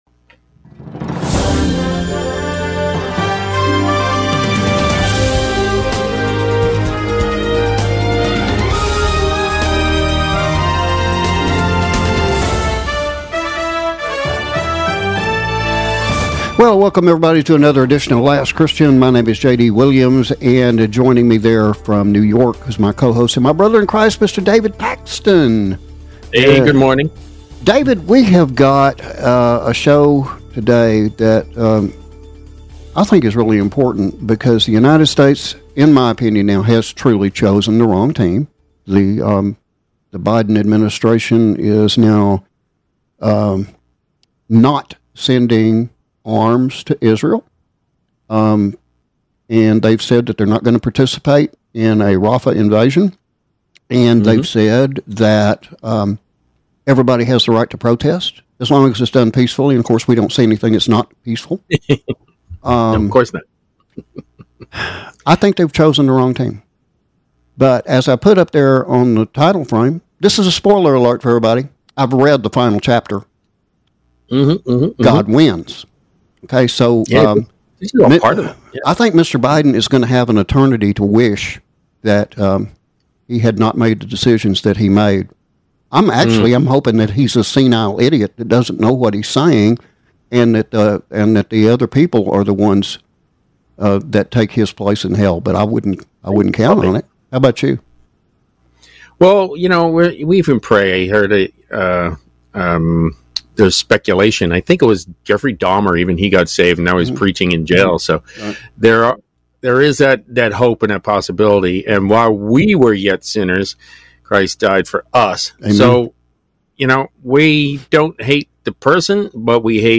We also apologize for the Audio issues experienced primarily in the first half hour of our International Syndicated Presentation.